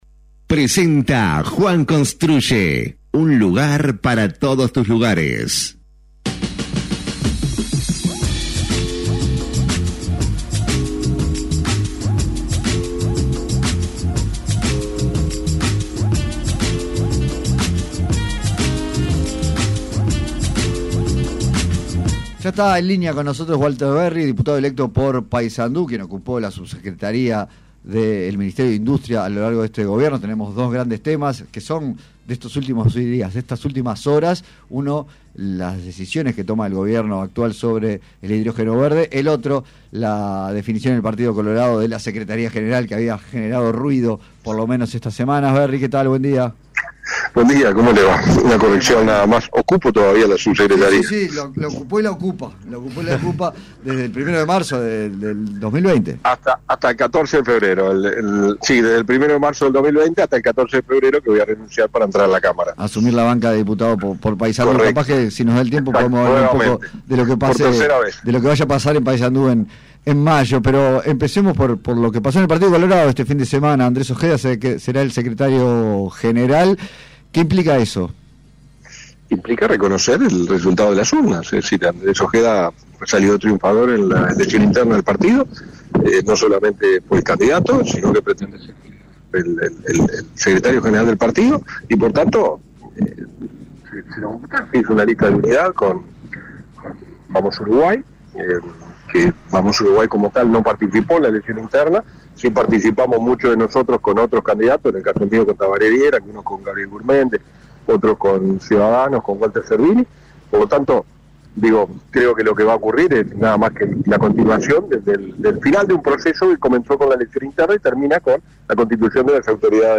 Entrevista completa a Walter Verri: